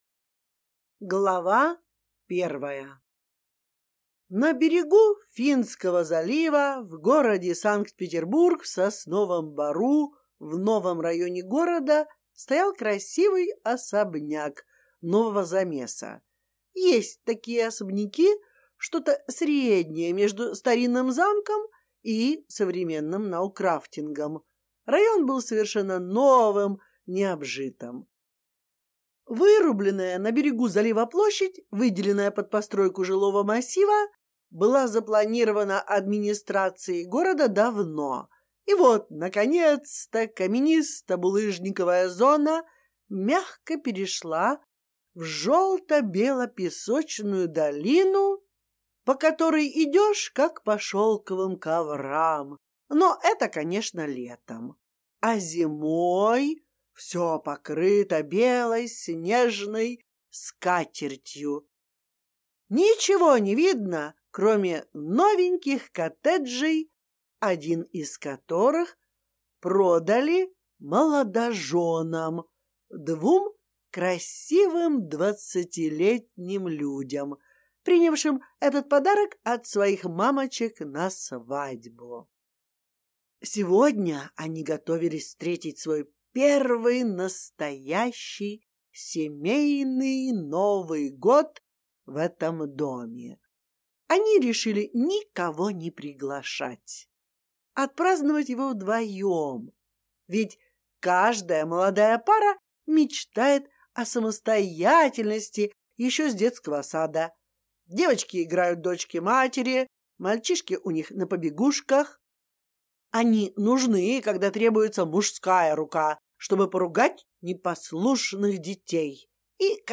Аудиокнига Сумасшедший Новый год!